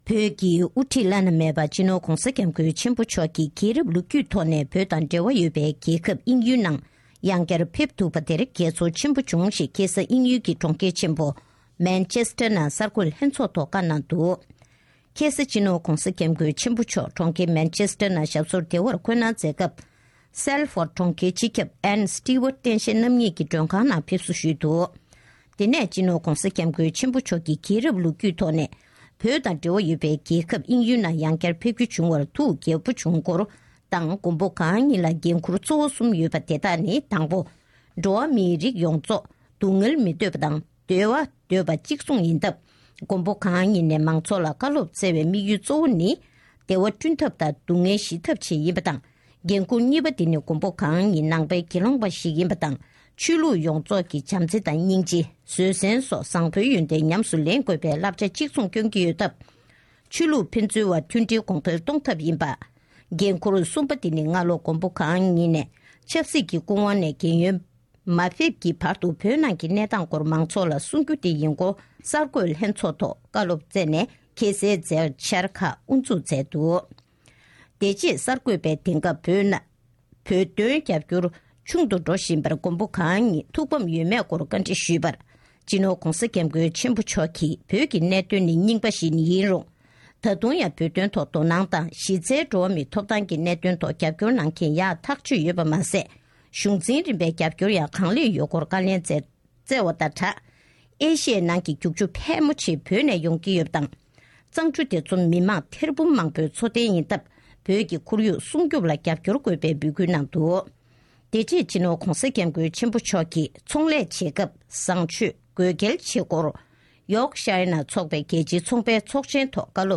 དབྱིན་ཡུལ་དུ་གསར་འགོད་ལྷན་ཚོཌ། ཕྱི་ཚེས་ ༡༦ ཉིན། ༸གོང་ས་མཆོག་ནས་དབྱིན་ཇིའི་གྲོང་ཁྱེར་མེན་ཅེ་སི་ཊར་དུ་གསར་འགོད་ལྷན་ཚོགས་ཐོག་བཀའ་སློབ་སྩོལ་བཞིན་པ།
སྒྲ་ལྡན་གསར་འགྱུར།